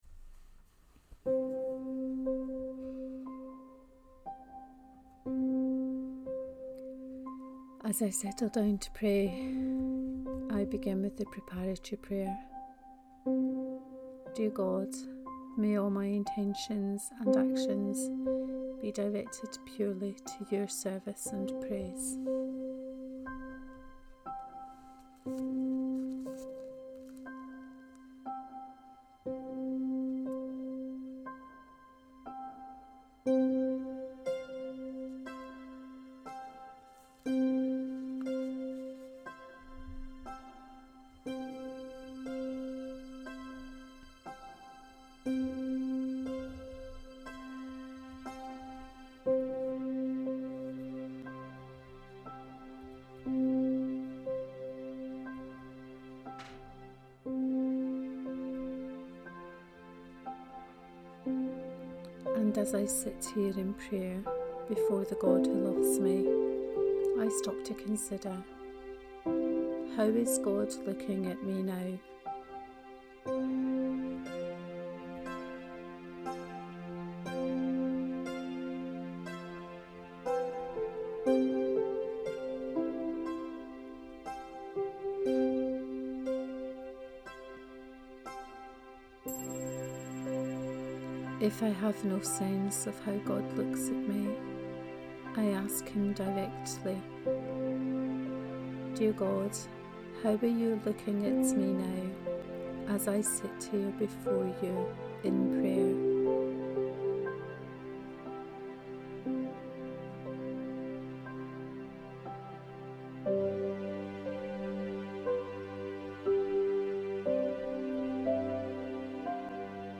Guided prayer on the Sunday Lectionary for the 6th Sunday in Ordinary time cycle B; year B.